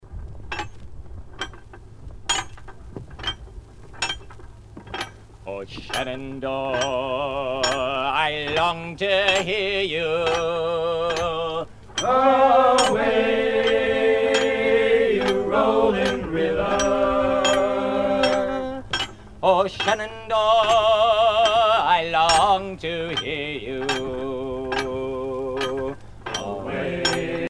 à virer au cabestan
Sea chanteys and sailor songs
Pièce musicale éditée